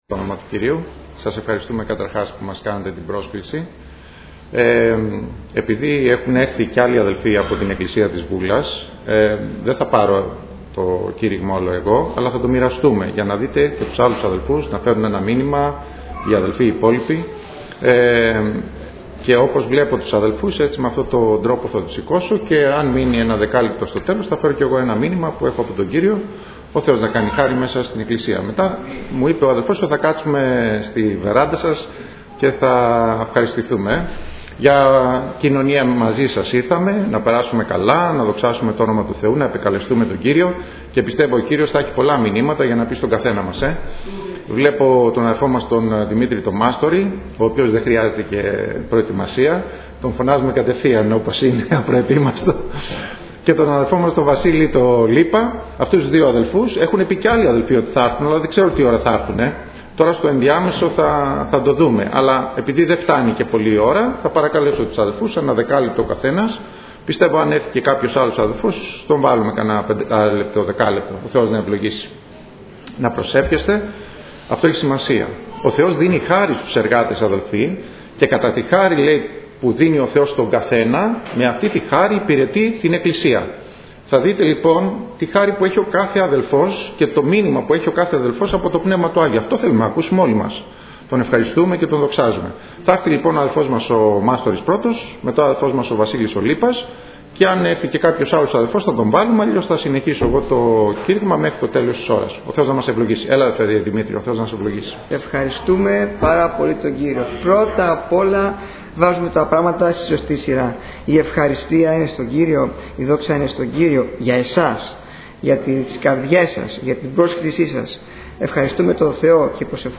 Ομιλητής: Διάφοροι Ομιλητές
Σειρά: Κηρύγματα